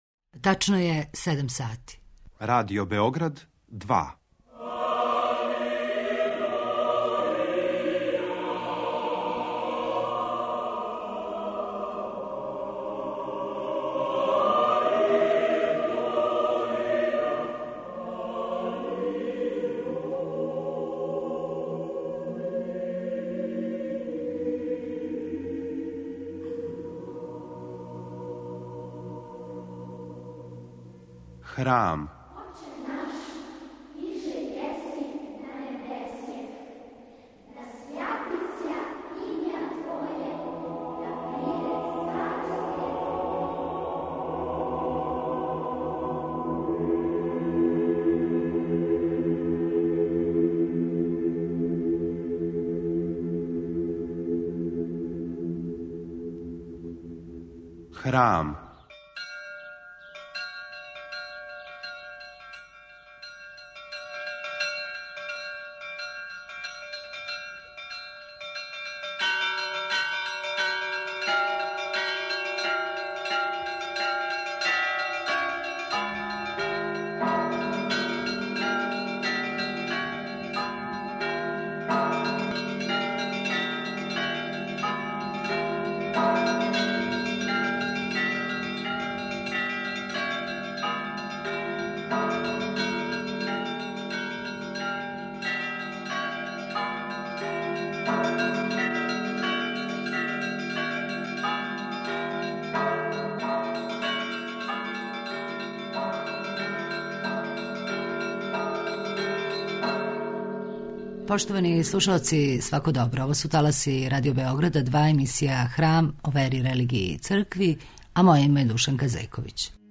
У овонедељном Храму слушамо његово високо преосвештенство митрополита црногорско-приморског Амфилохија, који говори шта је то видовданска етика и косовски завет. Предавање је одржано у крипти која је посвећена Светом кнезу Лазару, Храма Светог Саве у Београду, и ми вам у даншњем издању преносимо одабране делове.